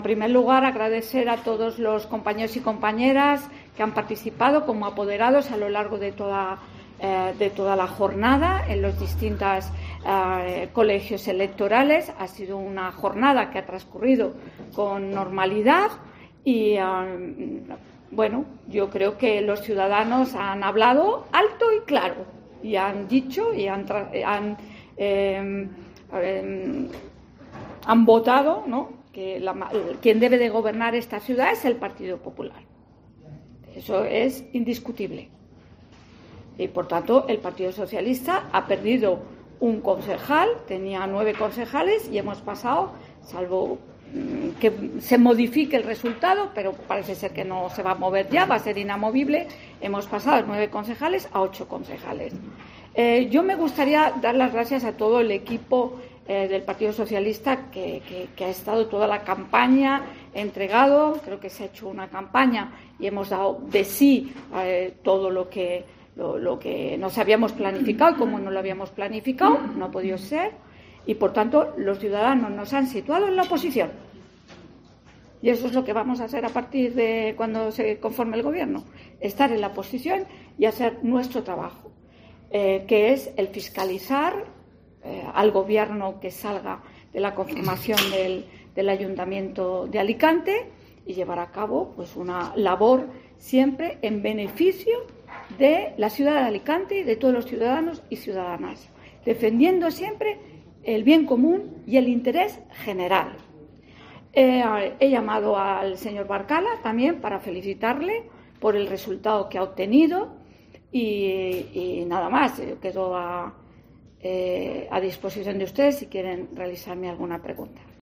Ana Barceló analiza los resultados del PSPV-PSOE en Alicante